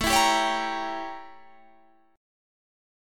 A7b9 Chord
Listen to A7b9 strummed